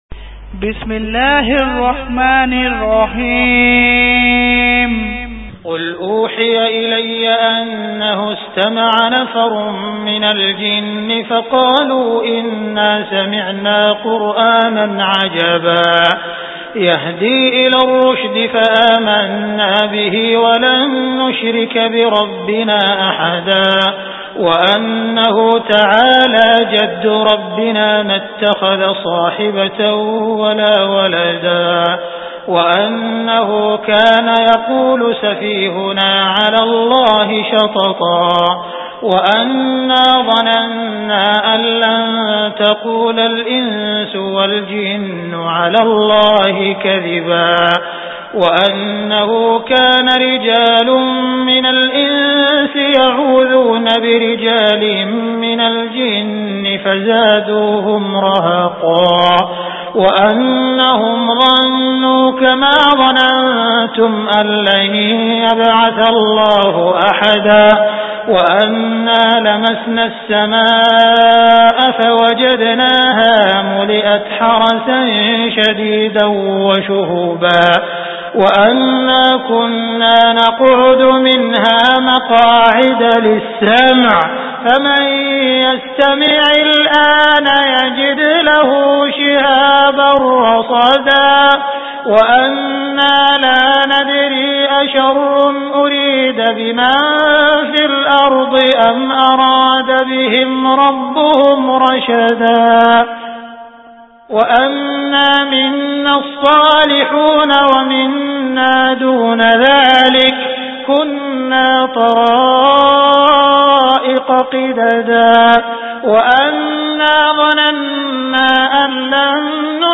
Surah Al Jinn Abdur Rahman As Sudais quran tilawat mp3 Download.